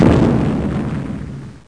explo3.mp3